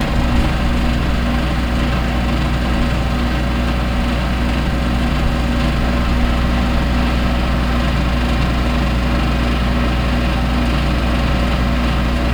FireplaceM.wav